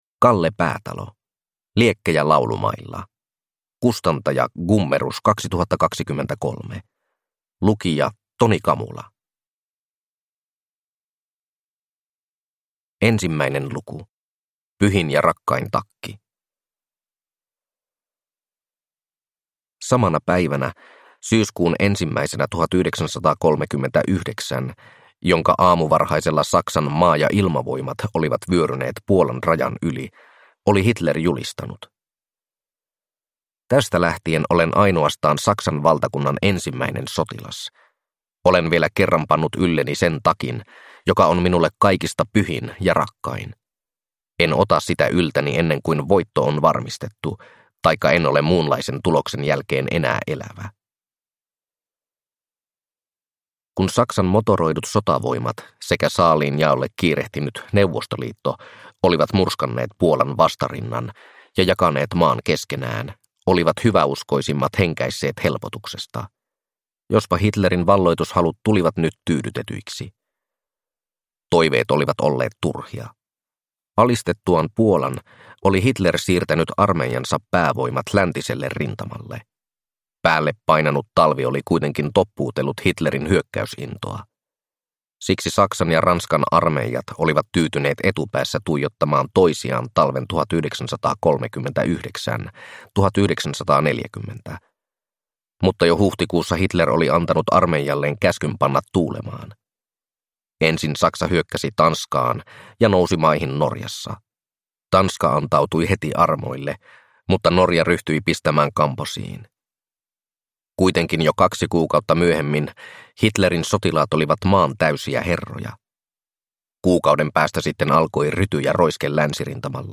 Liekkejä laulumailla – Ljudbok